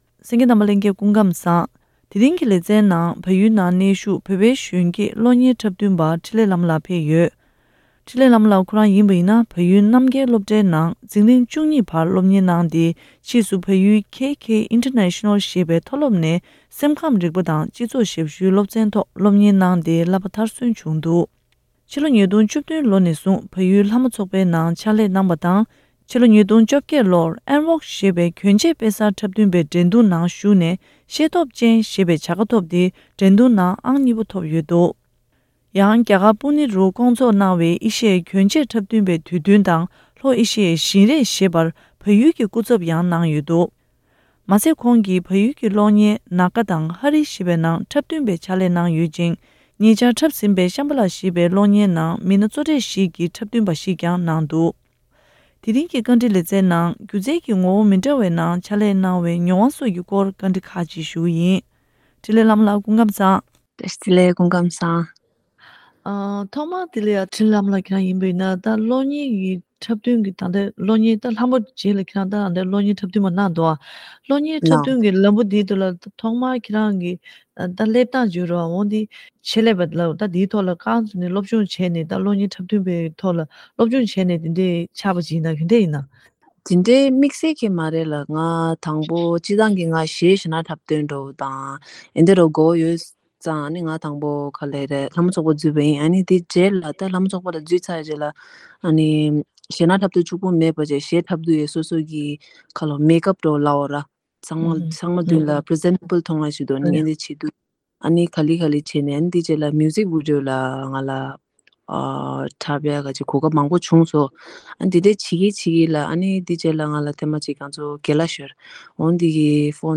དམིགས་བསལ་བཅར་འདྲིའི་ལས་རིམ་འདིའི་ནང་།